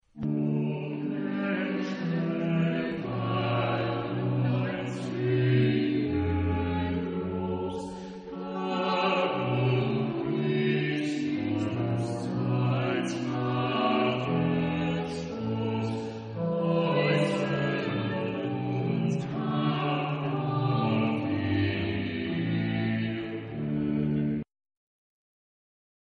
Genre-Style-Forme : Choral ; Sacré
Type de choeur : SATB  (4 voix mixtes )
Instruments : Orgue (1) ad lib
Tonalité : mi majeur